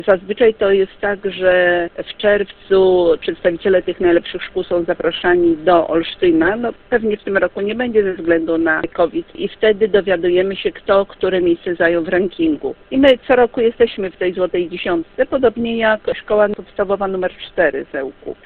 mówi w rozmowie z Radiem 5